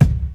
• Classic Hip-Hop Kick Drum Single Shot C# Key 264.wav
Royality free kick drum single hit tuned to the C# note. Loudest frequency: 211Hz